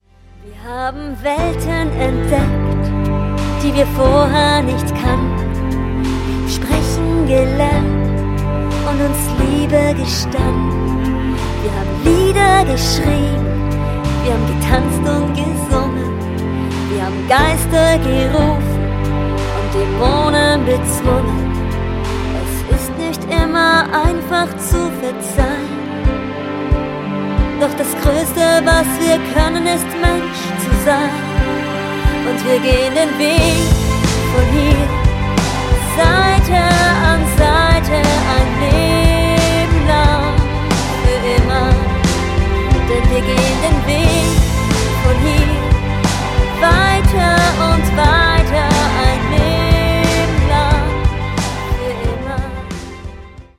Solo oder Duo
Hochzeitssängerin